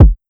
Go Head Kick.wav